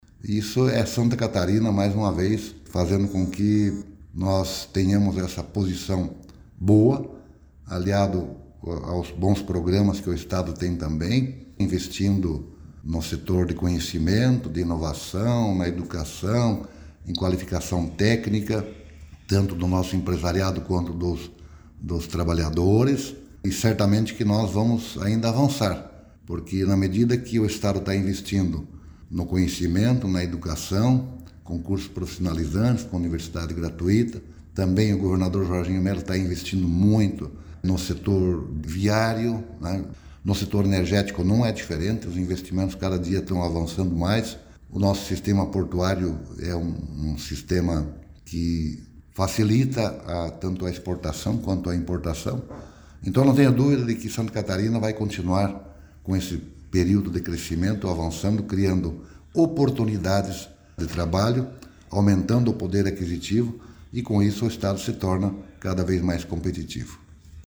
O secretário de Estado da Indústria, Comércio e Serviço, Silvio Dreveck, ressalta que Santa Catarina vai continuar com este período de crescimento pelos programas e incentivos na economia feitos pelo Governo do Estado: